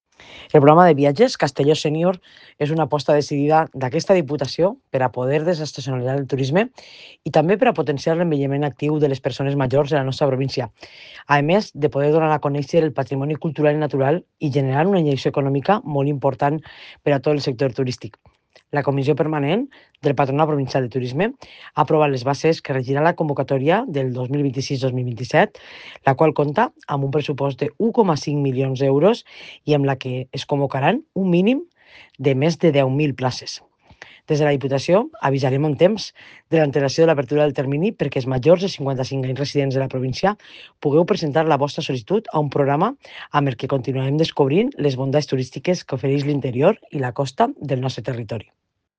Presidenta-Marta-Barrachina-aprobacion-bases-Castellon-Senior.mp3